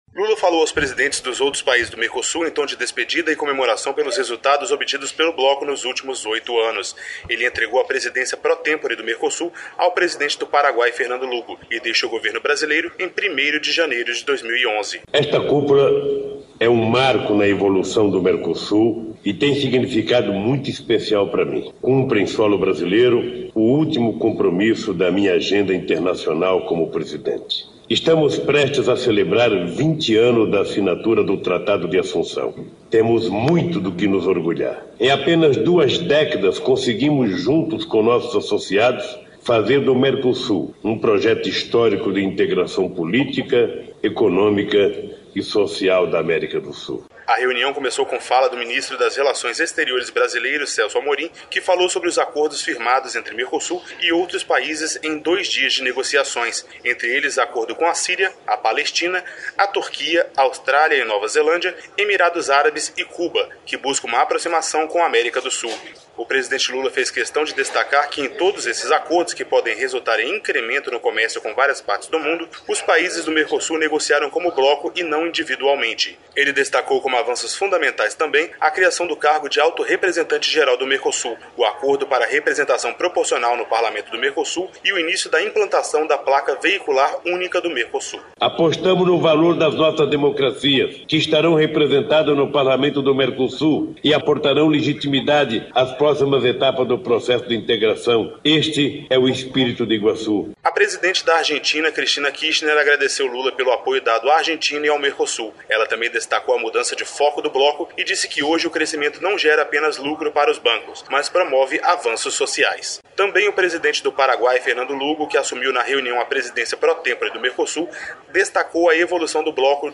Lula falou aos presidentes dos outros países do Mercosul em tom de despedida e comemoração pelos resultados obtidos pelo bloco nos últimos oito anos. Ele entregou a presidência pro tempore do Mercosul ao presidente do Paraguai, Fernando Lugo, e deixa o governo brasileiro em 1º de janeiro de 2011.